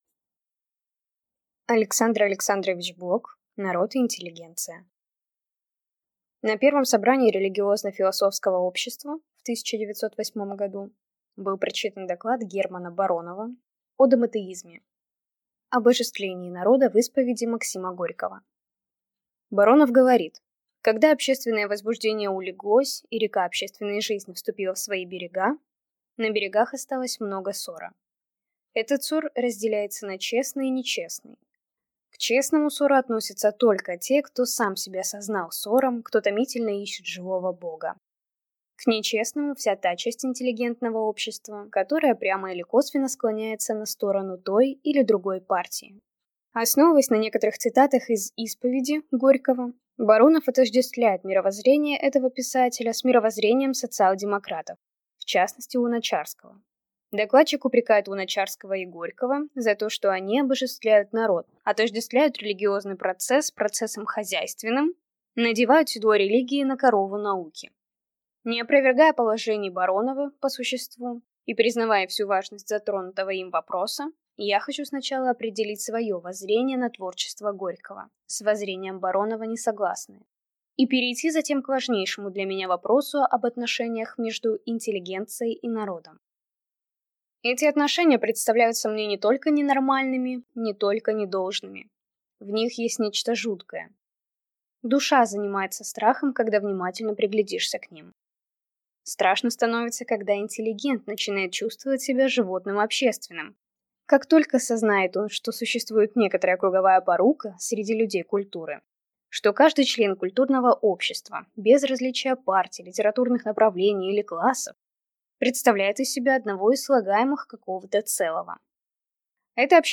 Аудиокнига Народ и интеллигенция | Библиотека аудиокниг